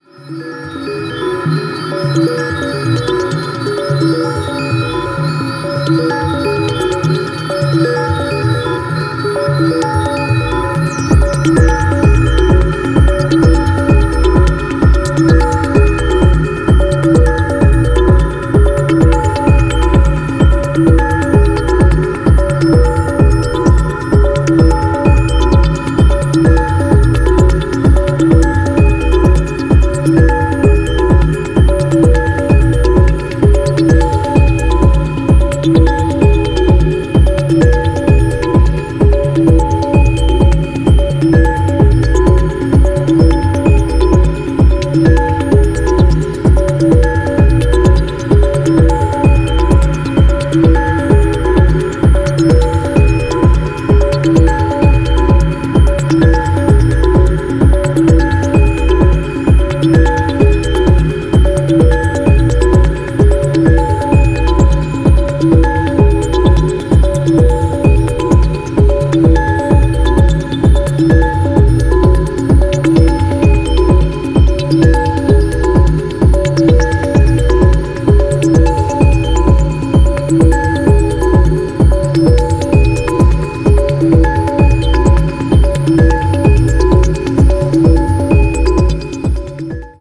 Pure interstellar techno tracks